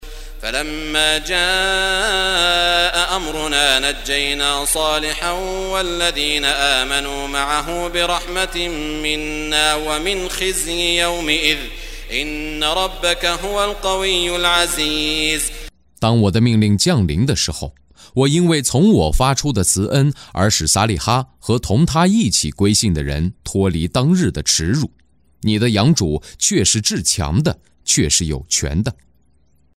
中文语音诵读的《古兰经》第（呼德章）章经文译解（按节分段），并附有诵经家沙特·舒拉伊姆的诵读